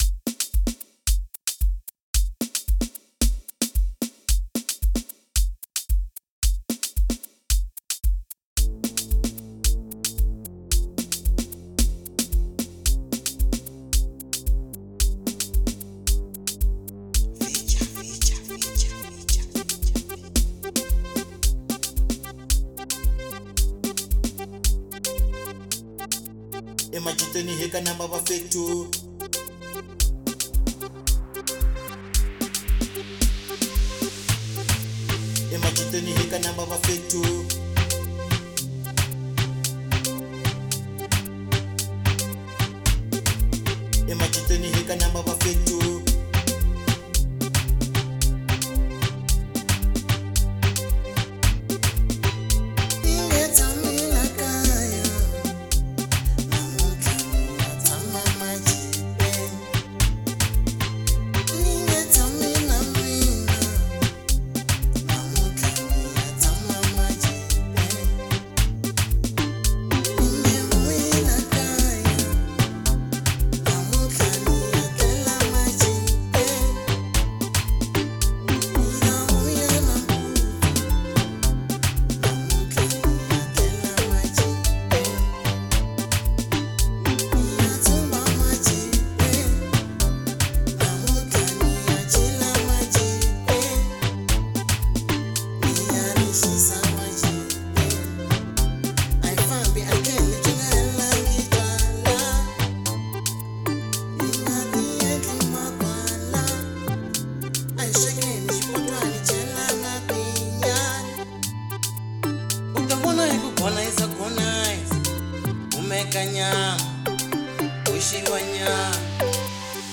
04:52 Genre : Amapiano Size